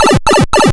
Pipe.wav